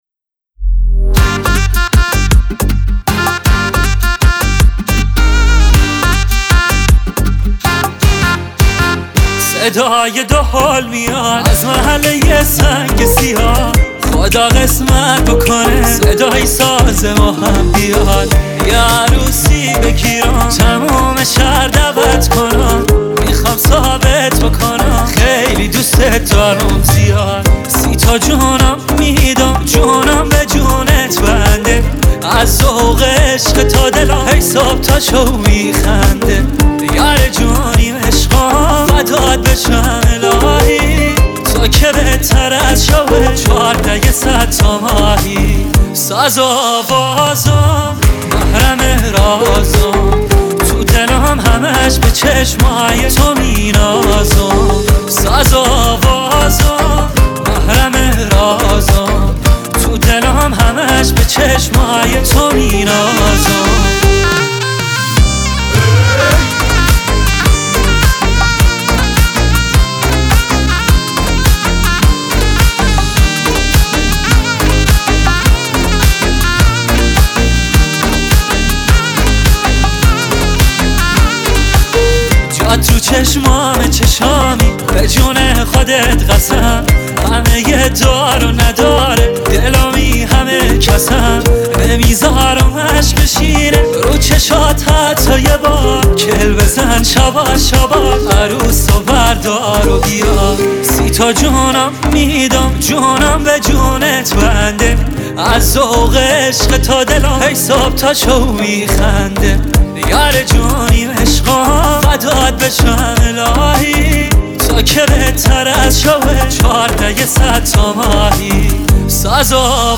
آهنگ عروسی